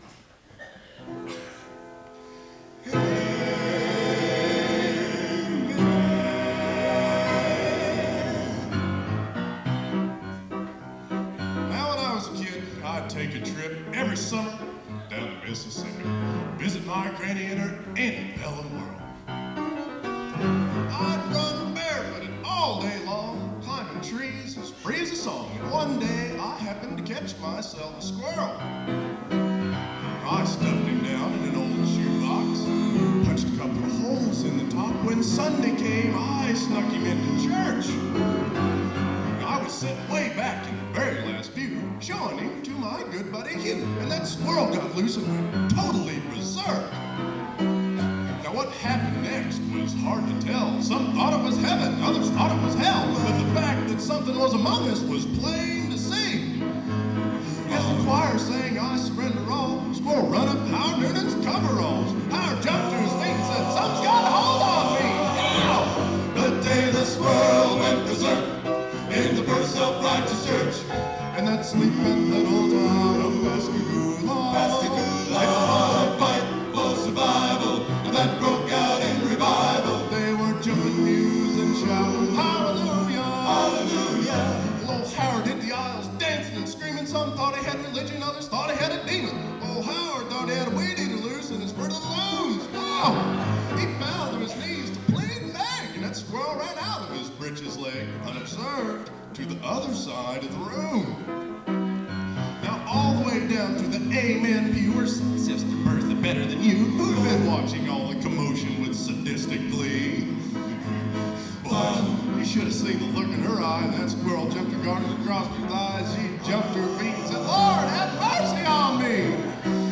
Genre: Gospel | Type: Specialty